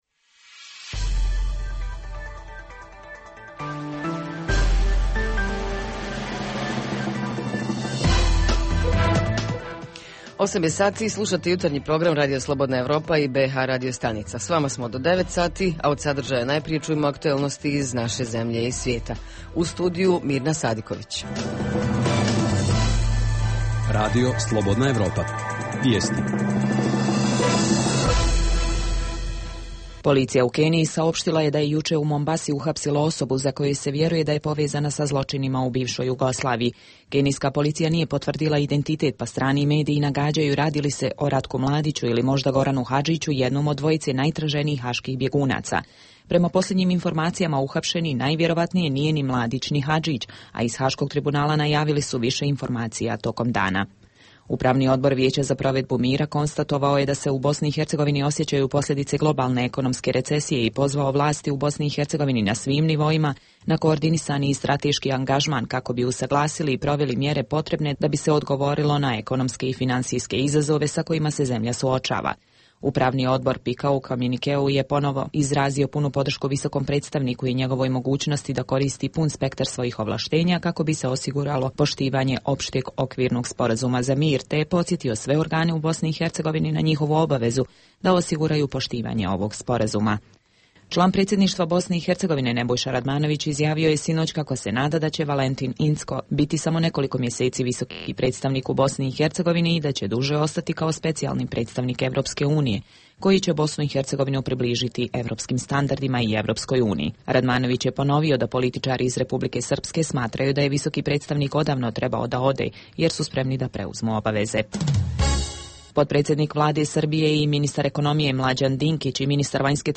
Jutarnji program za BiH koji se emituje uživo. 27. mart se obilježava kao Međunarodni dan teatra, pa govorimo o tome kako rade pozorišta, koliko im se pomaže iz budžeta, koliko se predstava godišnje uspije uraditi, kako pozorišta stoje sa kadrom itd.
Redovni sadržaji jutarnjeg programa za BiH su i vijesti i muzika.